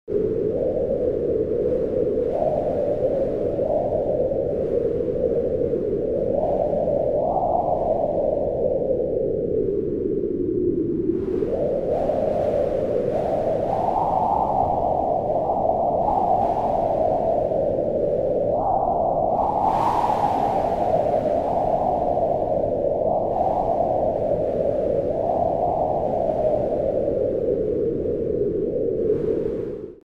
جلوه های صوتی
دانلود صدای باد 27 از ساعد نیوز با لینک مستقیم و کیفیت بالا